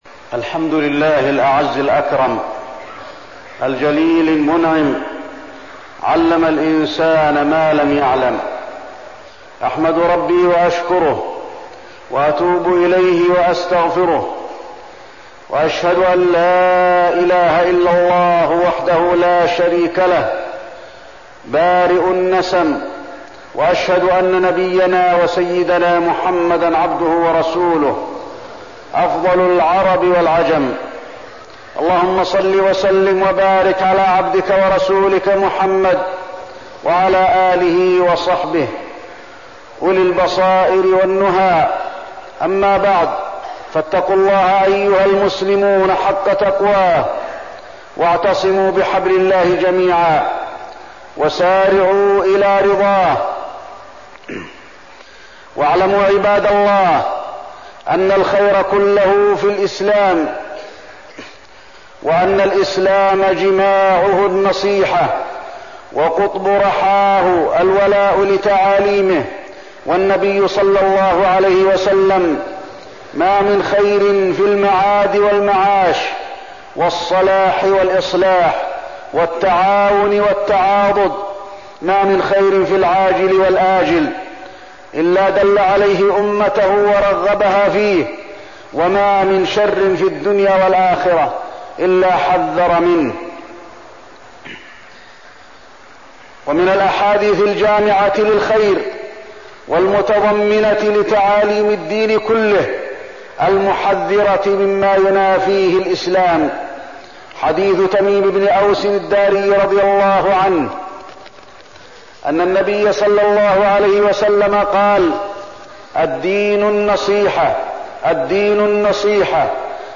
تاريخ النشر ٢٥ ربيع الثاني ١٤١٥ هـ المكان: المسجد النبوي الشيخ: فضيلة الشيخ د. علي بن عبدالرحمن الحذيفي فضيلة الشيخ د. علي بن عبدالرحمن الحذيفي النصيحة